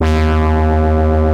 Bass x-tra 1.109.wav